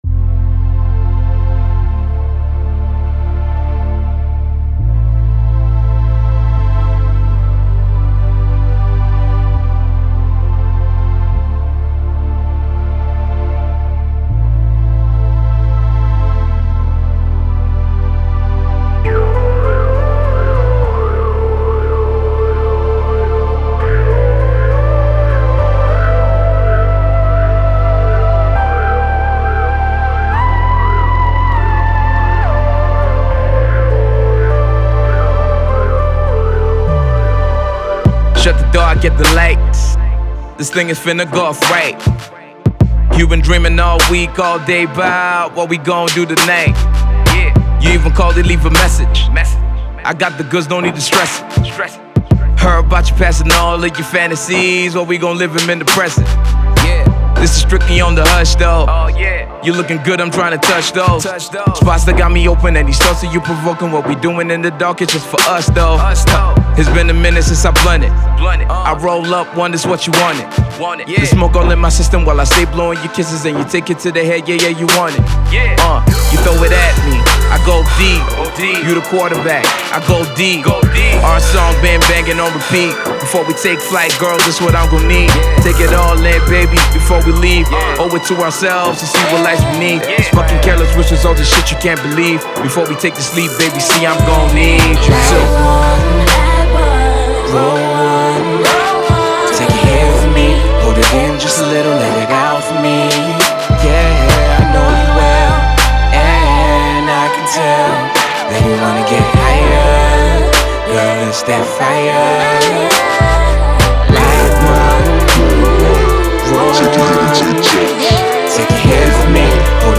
Hip-Hop
Rapper